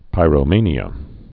(pīrō-mānē-ə, -mānyə)